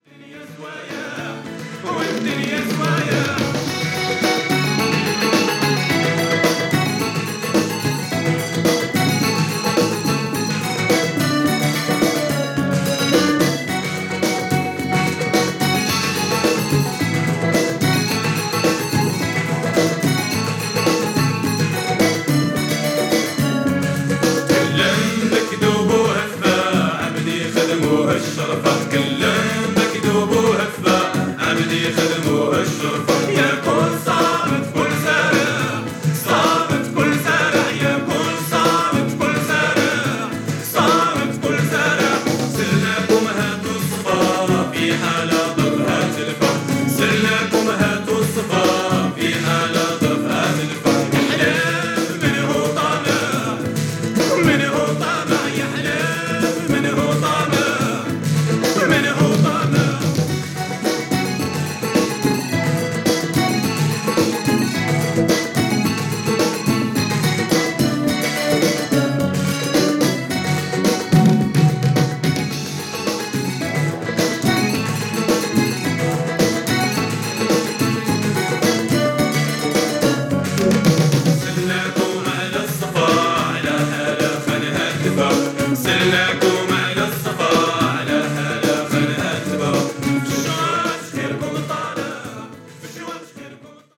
Cosmic